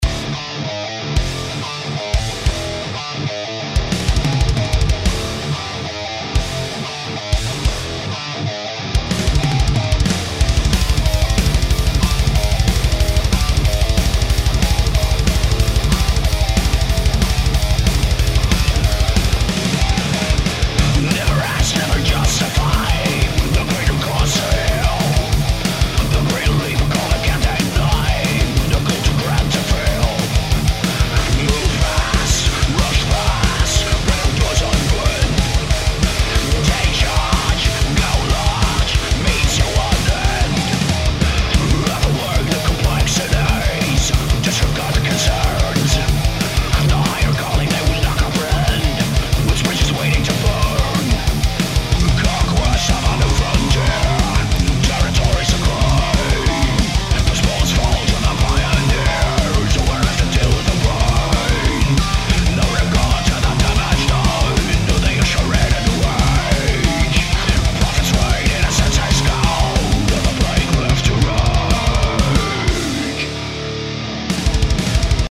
Input zu Thrash Metal Mix
Leider hat er vor allem auf der Stimme sehr viele Artefakte.
Gehen wir mal durch: Bei den Drums merkt man sehr, dass es programmiert ist. Die Kick ist mir etwas zu clicky. das liegt aber eher daran, dass meiner Meinung nach auf der Bassdrum ein kleiner Boost von 2-3db im Bereich um 60hz sein könnte, damit das ganze etwas mehr Glue bekommt. Die Drums klingen noch etwas trocken.
Arbeite erstmal nur mit den Fadern von deiner DAW Bei den Vocals hast du beim Bereich um 1,7khz eine Frequenz, welche mit den Gitarren kämpft.
Um 4khz ist ein "Whisteling" Effekt bei der Gitarre, den würde ich auch rausnotchen.
Der Bass klingt mir persönlich noch zu sehr nach DI und ist auch viel zu leise.
Die Aufnahmen sind sauber.